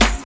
22 SNARE.wav